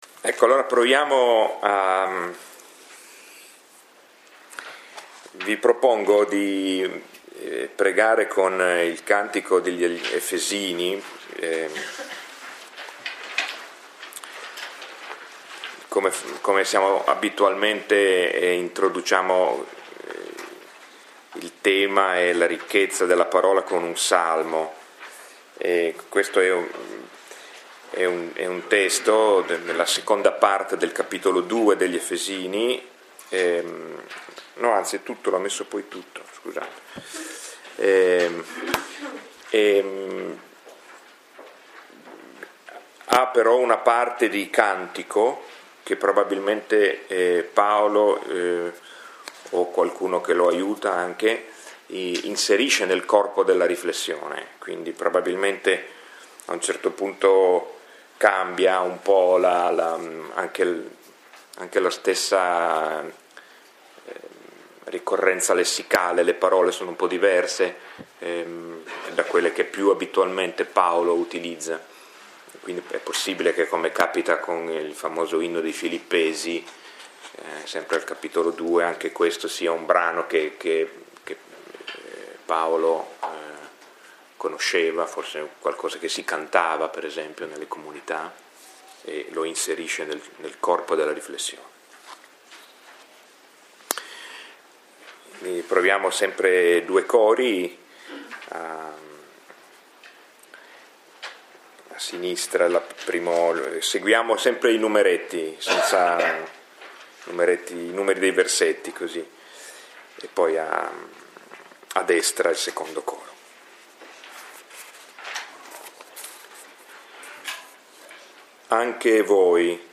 Lectio 7 – 21 maggio 2017